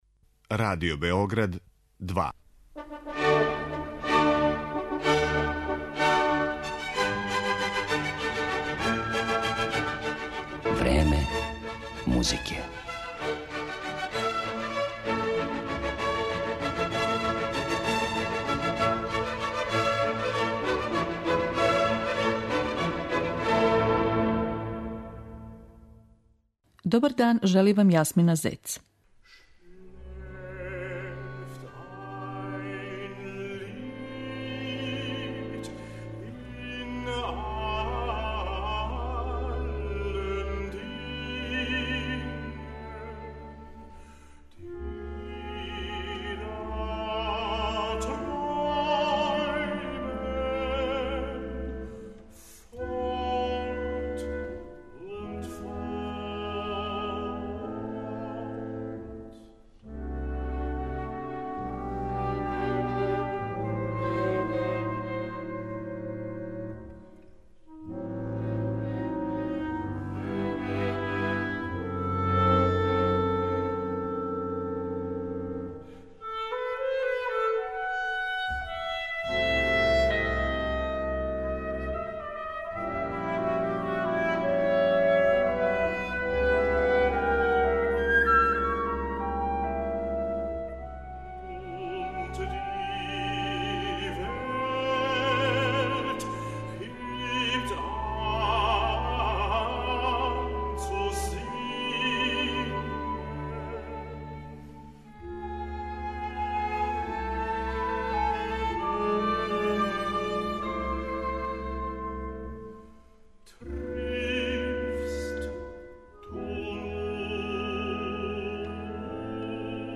Изабрали смо део програма који је овај познати певач извео пре две године у концертној дворани, па ће слушаоци данас моћи да чују песме Роберта Шумана и Густава Малера, које ће са Кристофом Прегардијеном интерпретирати и ансамбл "Контраст".